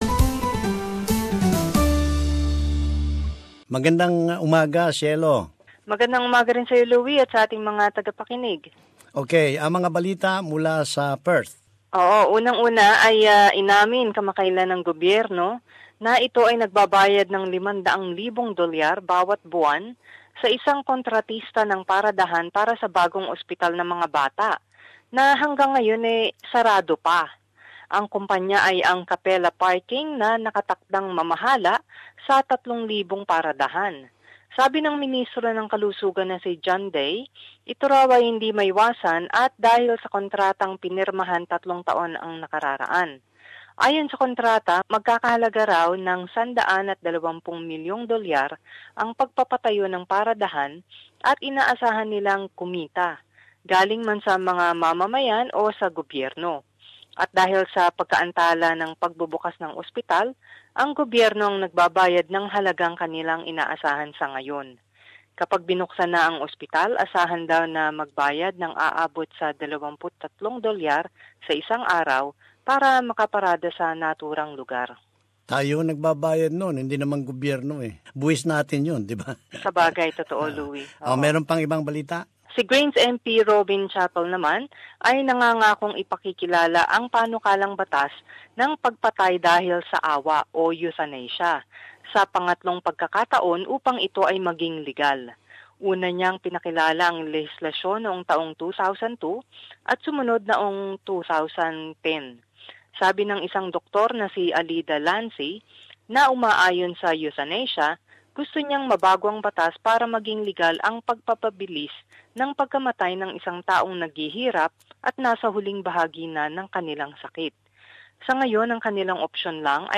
Perth report